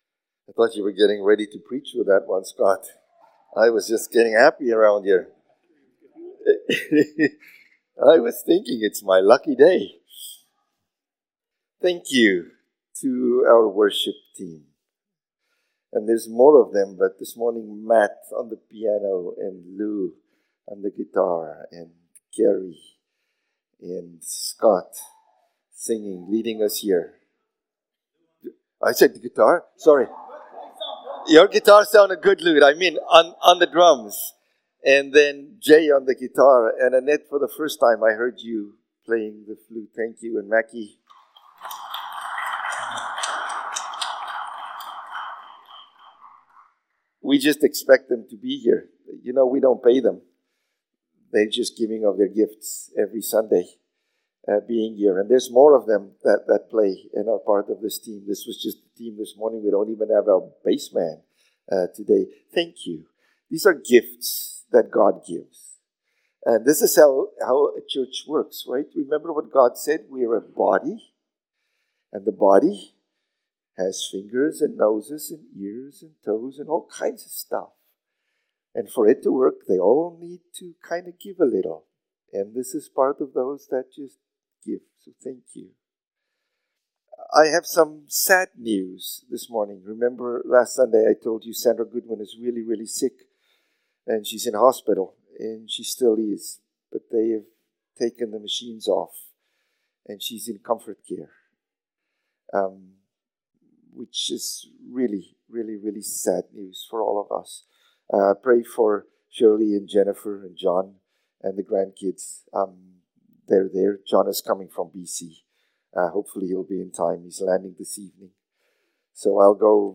August-24-Sermon.mp3